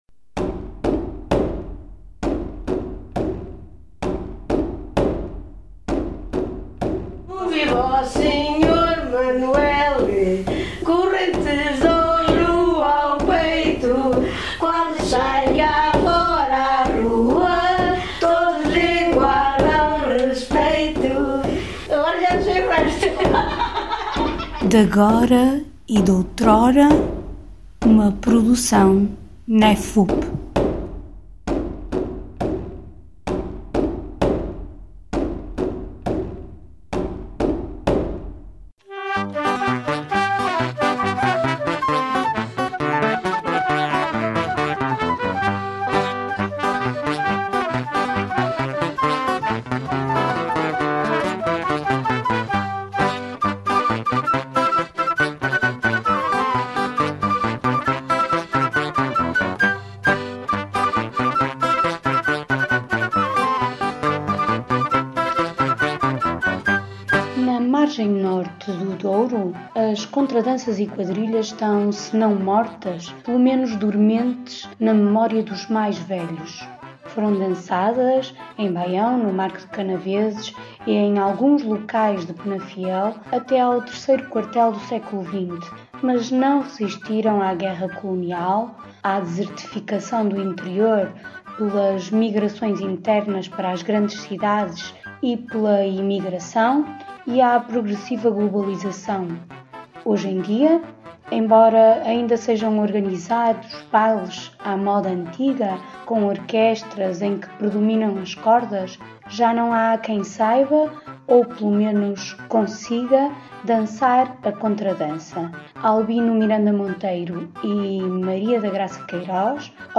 à conversa com os convidados
ao som da música de vários grupos da região.